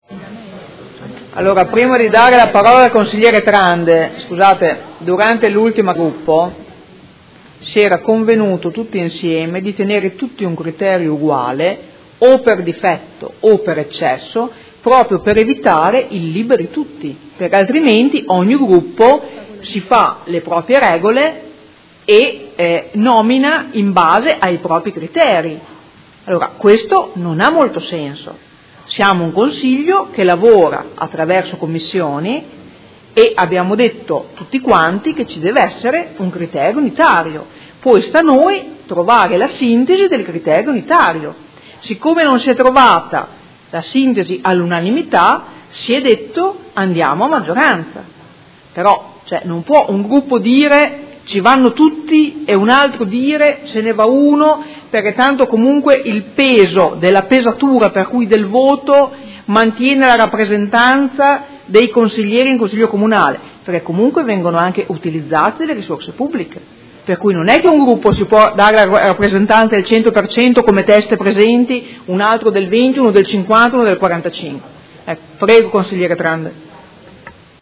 Presidente — Sito Audio Consiglio Comunale
Interviene su dibattito